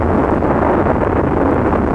KART_turboLoop.ogg